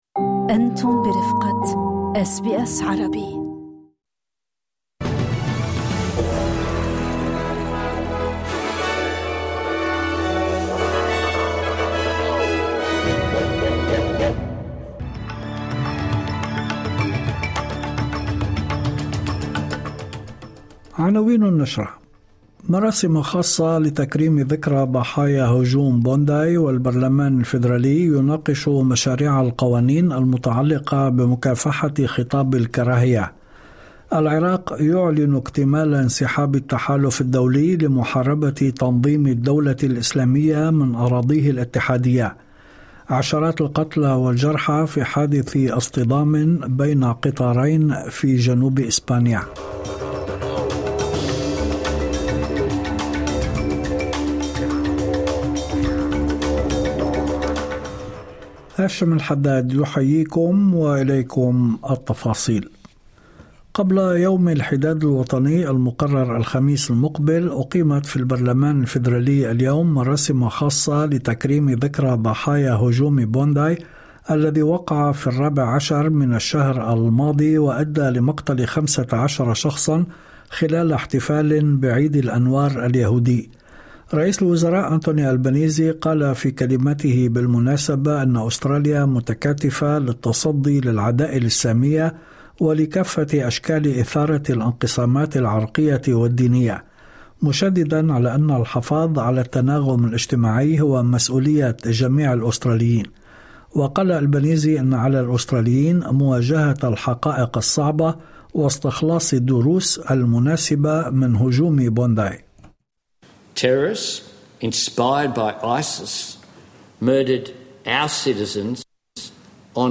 نشرة أخبار المساء 19/01/2026